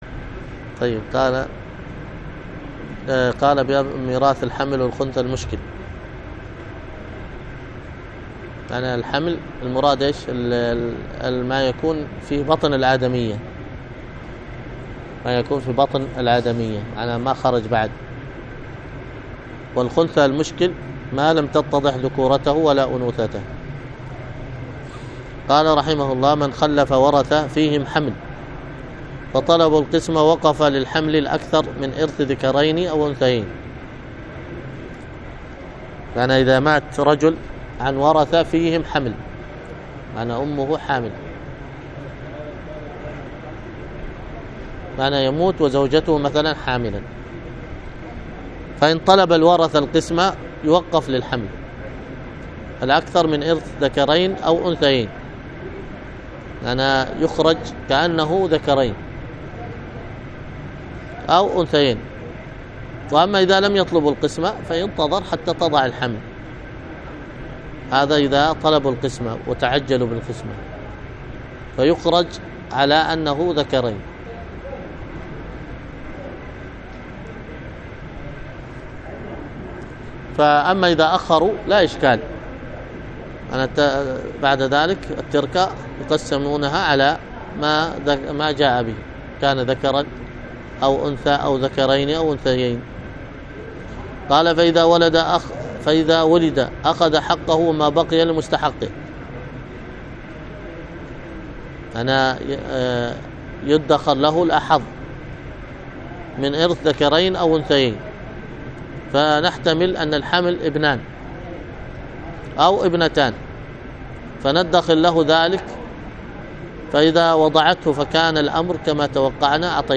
الدرس في شرح منظومة أصول الفقه وقواعده 5